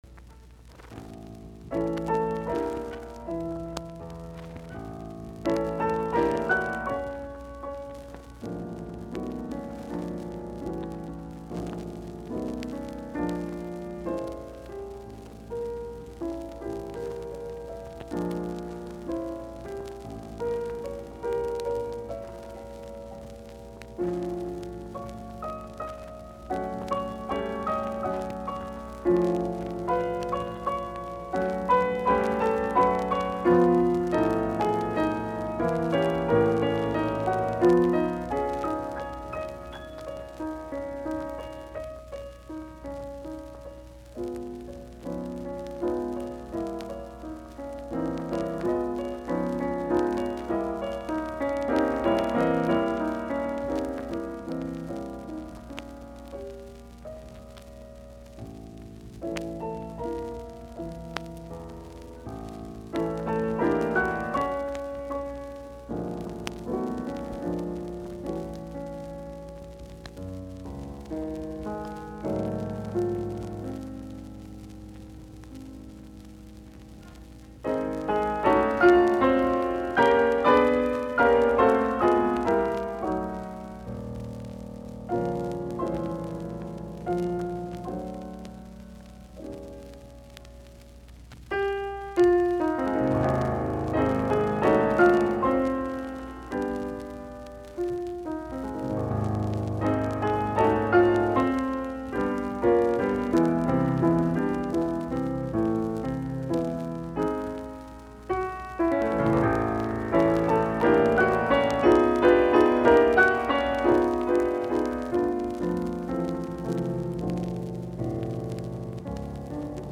Soitinnus : Piano